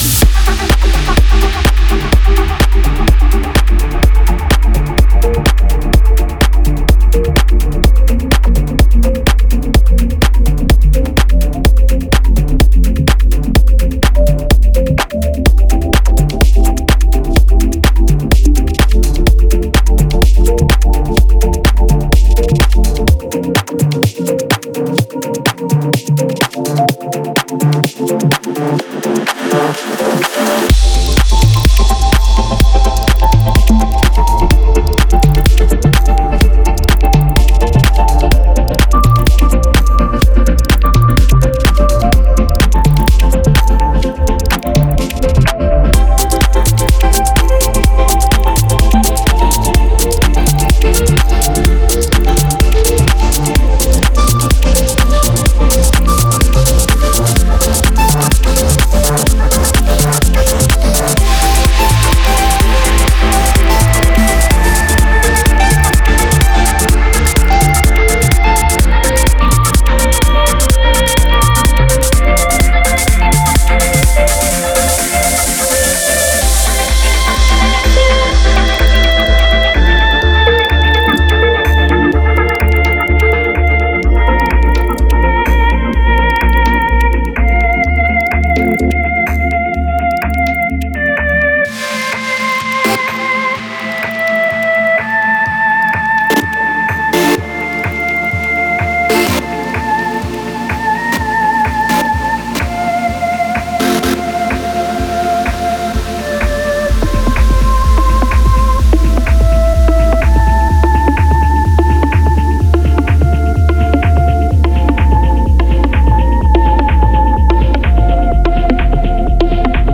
Стиль: Progressive House